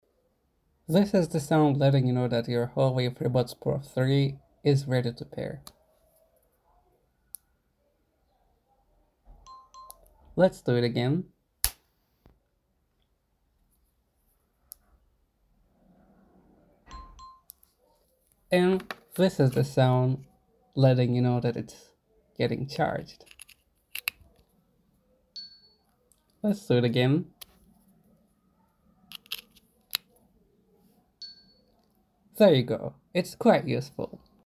Upon opening the case for the first pairing, a pleasant sound indicates the buds are ready to be paired, which also occurs when the buds start charging with a cable.
Huawei-FreeBuds-Pro-3-sounds.mp3